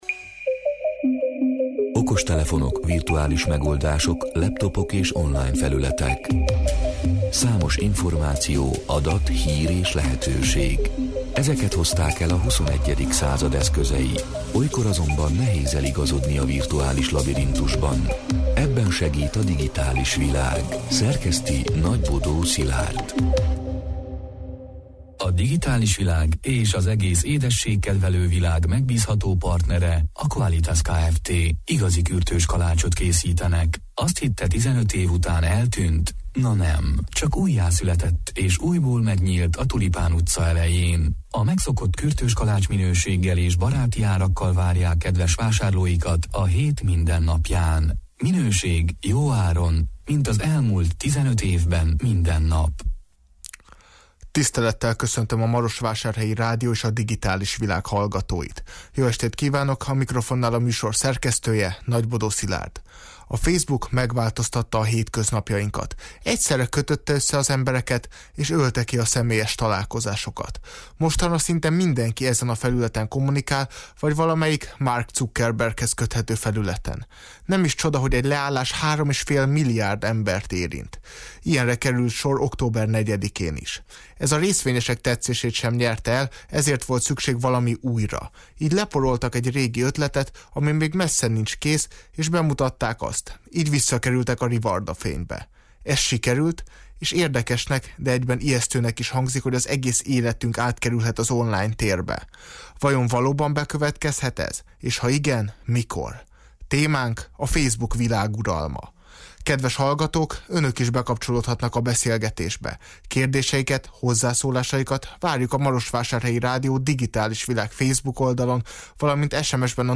(elhangzott: 2021. november 9-én, 20 órától élőben)